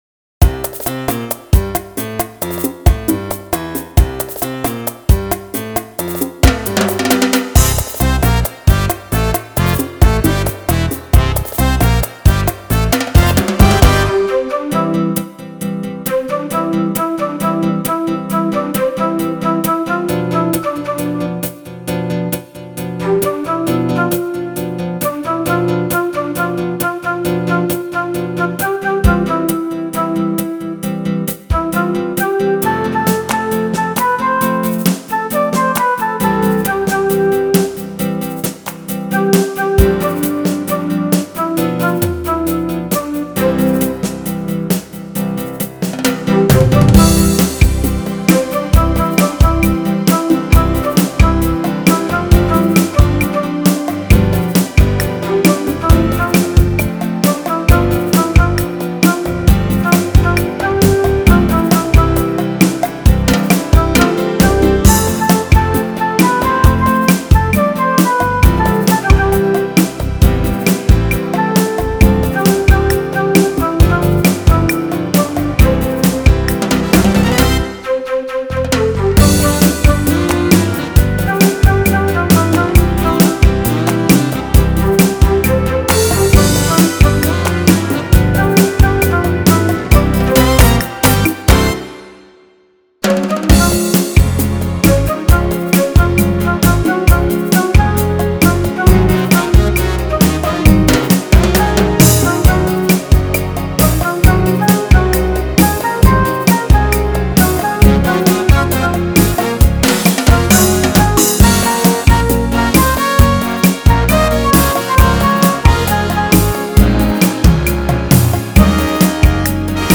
thLLCOOJVU  Download with Lead